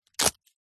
Звуки билета
Звук печатающего билеты аппарата